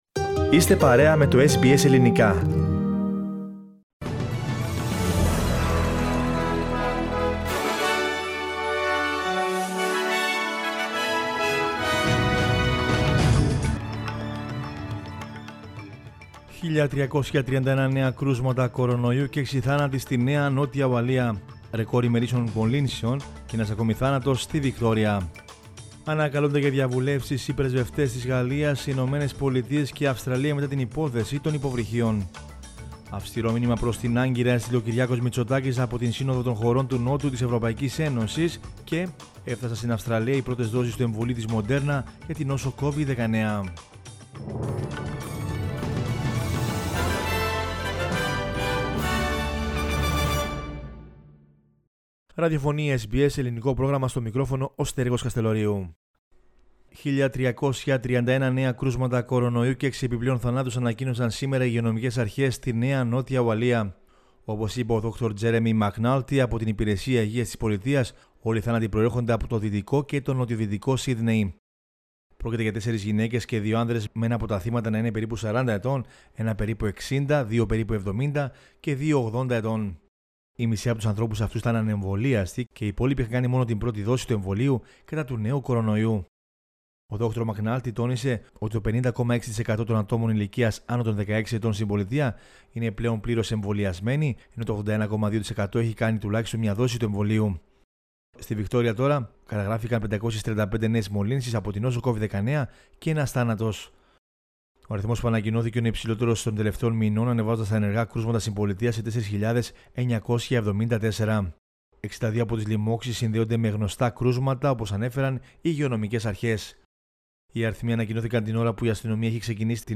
News in Greek from Australia, Greece, Cyprus and the world is the news bulletin of Saturday 18 September 2021.